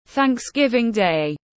Ngày Lễ Tạ Ơn tiếng anh gọi là Thanksgiving Day, phiên âm tiếng anh đọc là /ˌθæŋksˈɡɪv.ɪŋ deɪ/
Thanksgiving Day /ˌθæŋksˈɡɪv.ɪŋ deɪ/
Thanksgiving-Day-.mp3